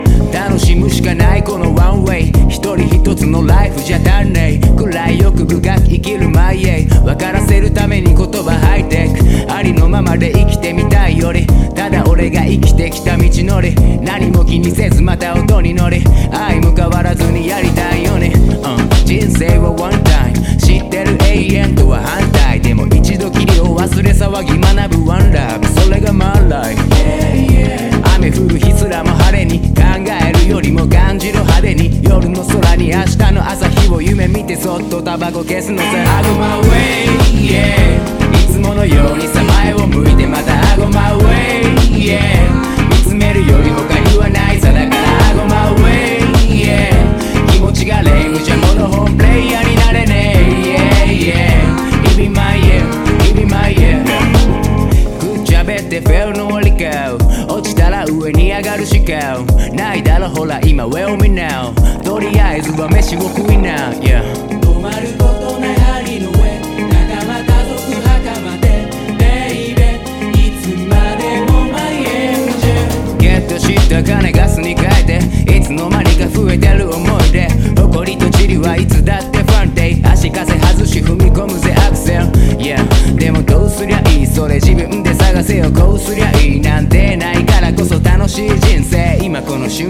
SURF / AMBIENT / JAPANESE CLUB / DUB / JAPANESE DUB
ドリーミィ・エキゾ・サーフ・チルアウト傑作7インチ2枚組！
海外でも評価の高い、ドリーミーでメロウ、メランコリックな気持ちいいサウンドは、今聴いても全く古くなってない新鮮さ！